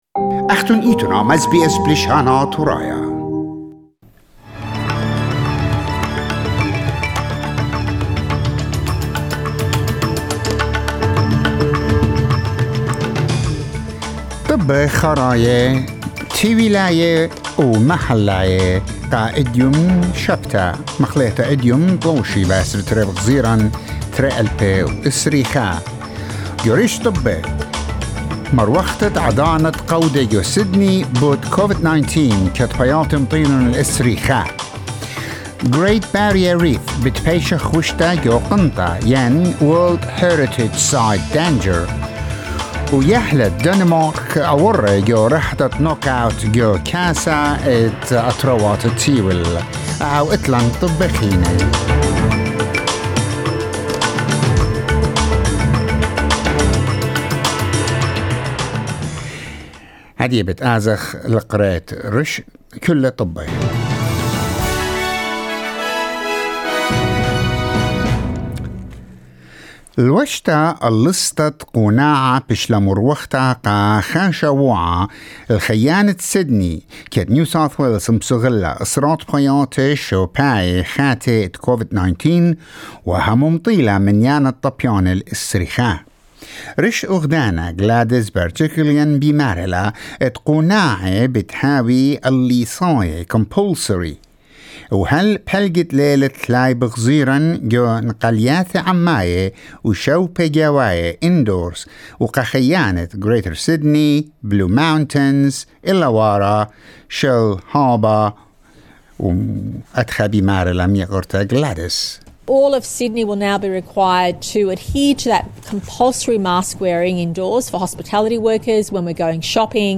Latest National and International News